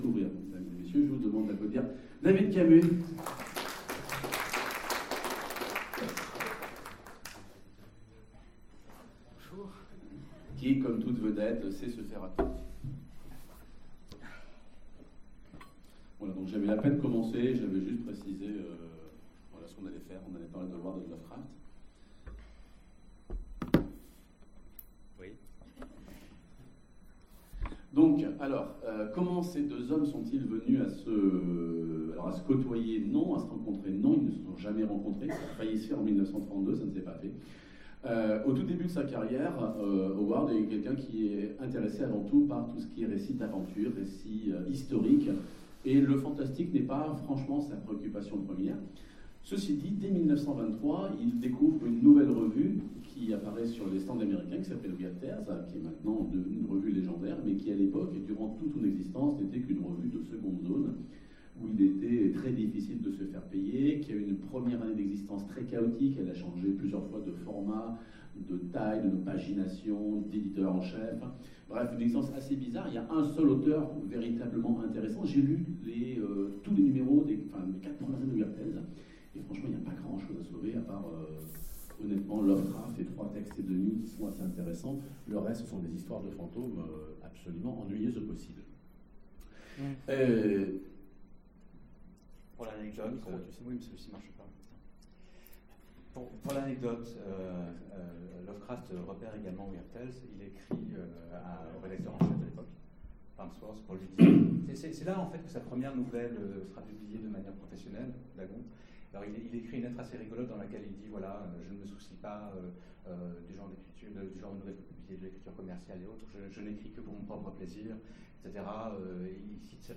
Conférence FMI 2017 : L'amitié Robert E. Howard et H. P. Lovecraft